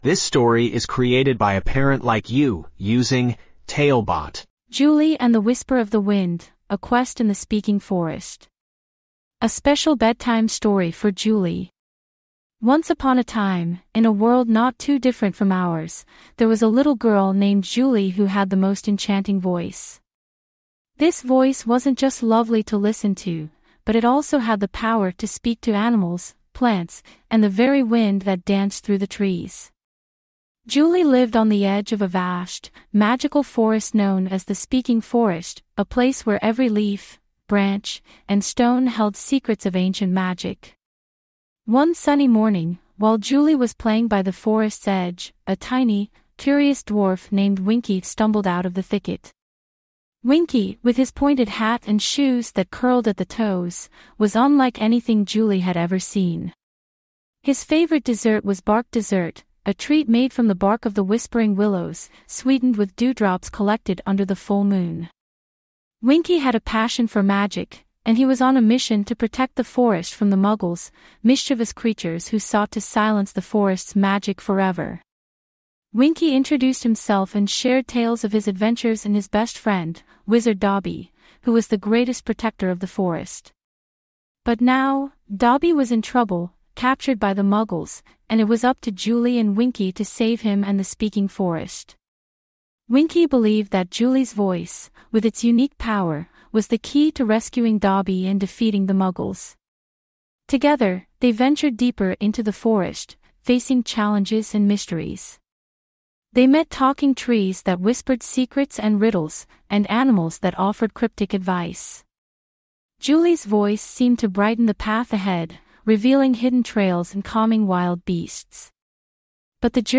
5 Minute Bedtime Stories